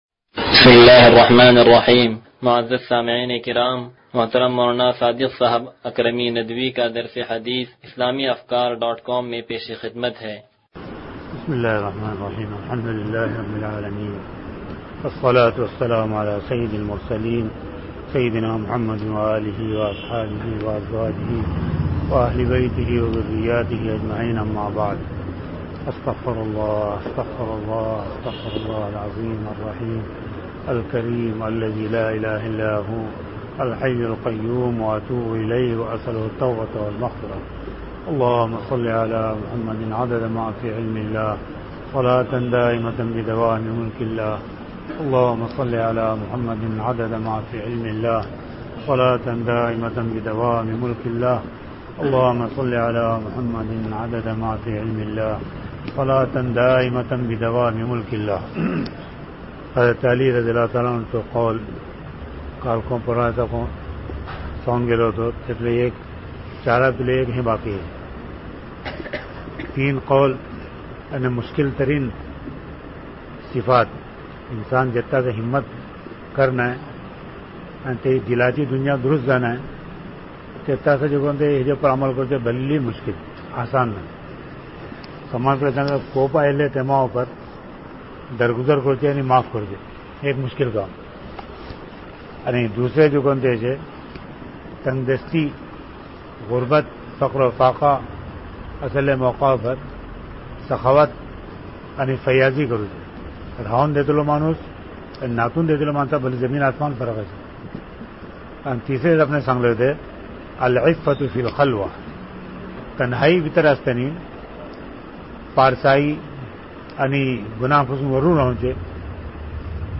درس حدیث نمبر 0095